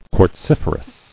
[quartz·if·er·ous]